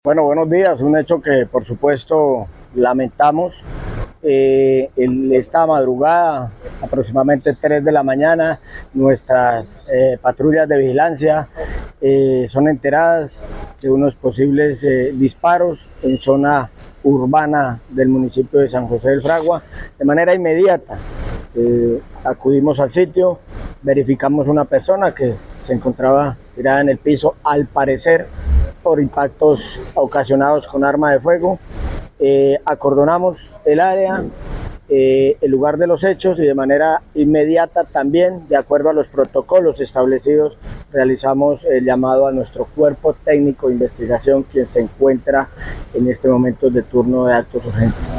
De acuerdo con el coronel, Julio Guerrero Rojas, comandante de Policía Caquetá, para el caso del homicidio de la mujer, los investigadores avanzan en la verificación de cámaras de seguridad con el fin de encontrar a los autores materiales de este crimen.
CORONEL_JULIO_GUERRERO_HOMICIDIOS_-_copia.mp3